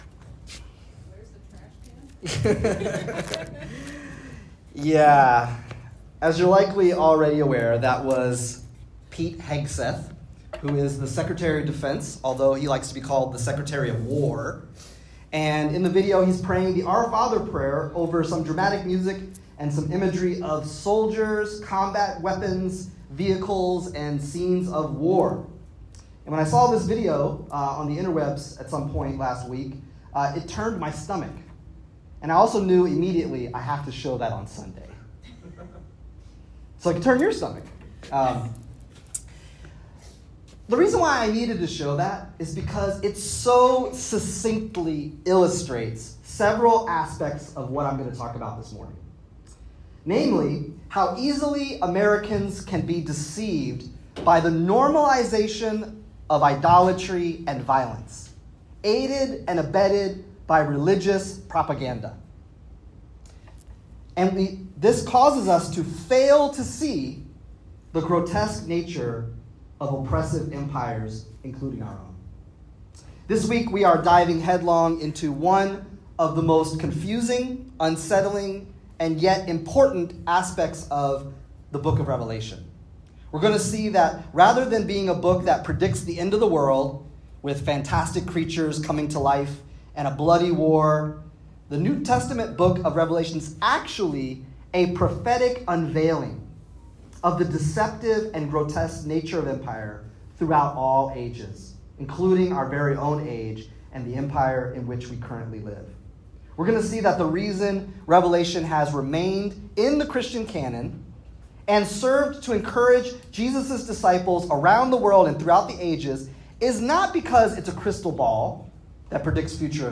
In this sermon, we explore how John’s visions of beasts, dragons, and Babylon aren’t predictions of the world’s end, but prophetic unveilings of empire’s true nature—then and now. Revelation exposes the seductive power of political and religious systems that disguise domination as righteousness and violence as peace.